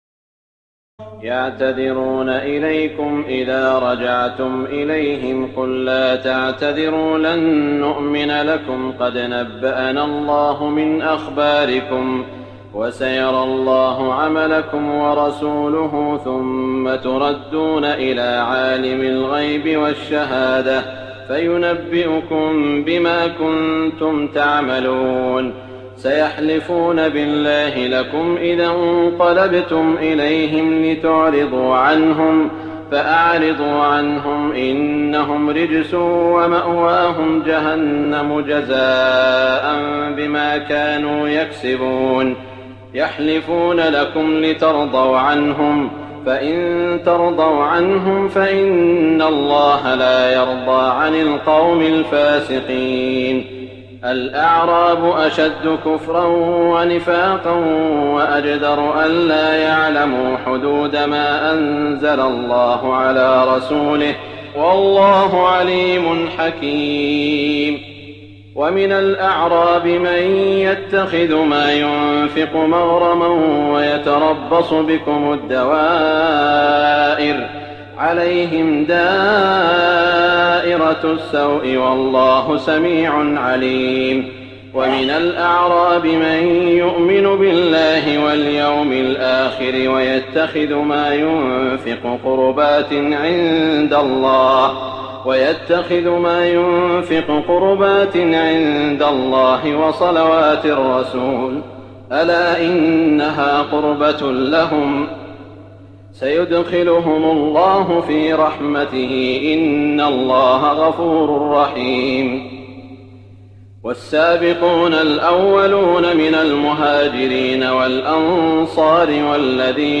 تراويح الليلة العاشرة رمضان 1419هـ من سورتي التوبة (94-129) و يونس (1-25) Taraweeh 10 st night Ramadan 1419H from Surah At-Tawba and Yunus > تراويح الحرم المكي عام 1419 🕋 > التراويح - تلاوات الحرمين